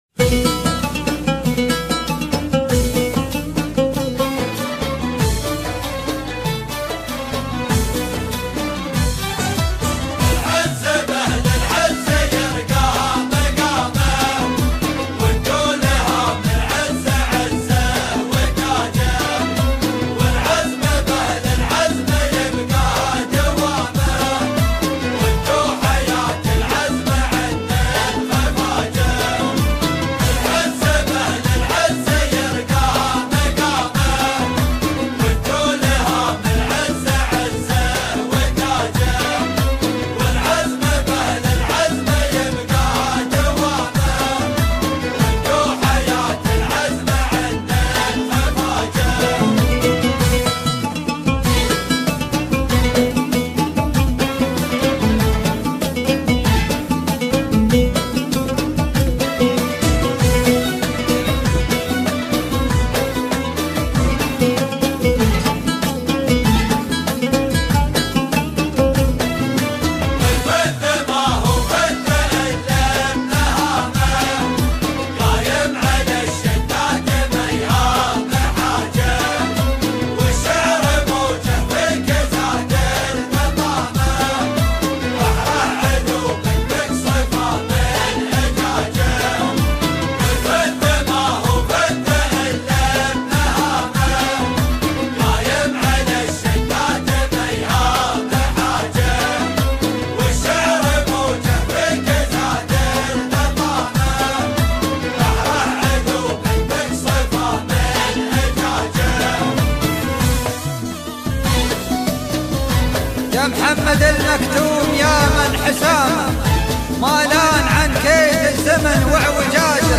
Musica Arabe De Dubai